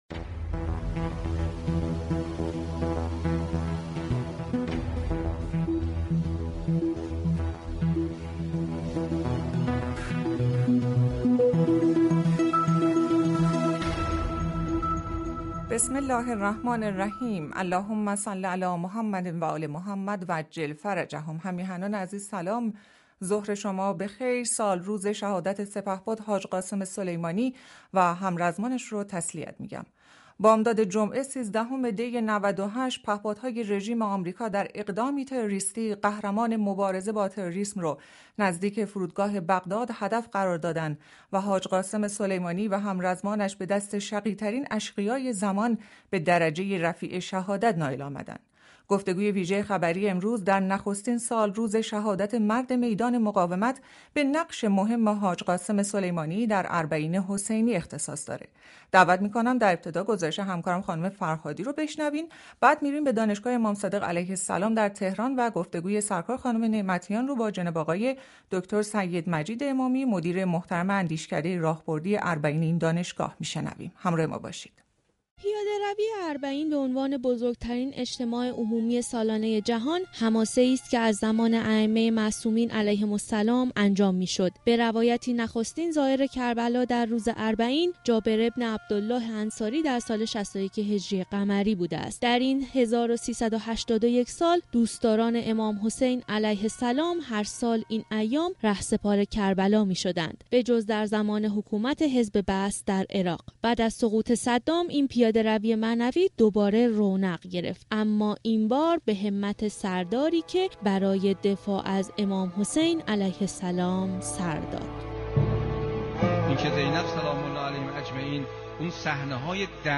گفتگوی ویژه خبری